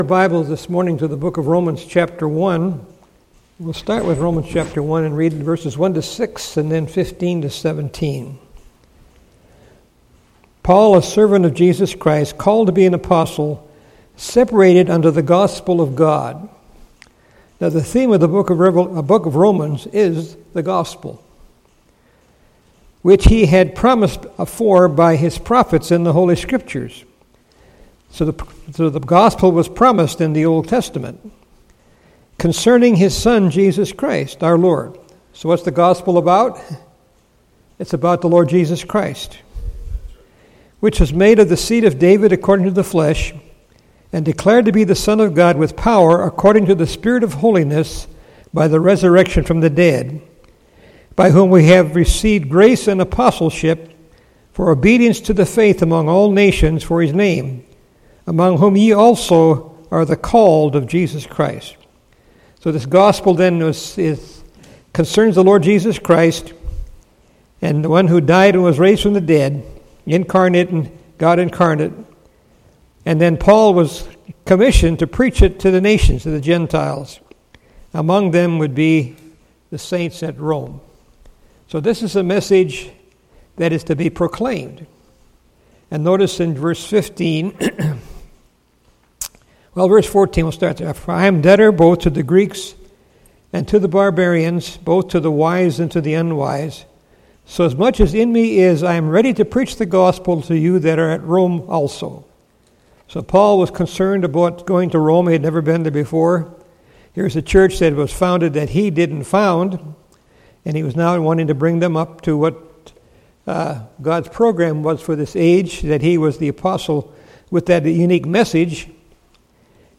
Series: 2018 August Conference Session: Morning Session